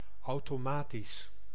Ääntäminen
France (Paris): IPA: [o.to.ma.tik]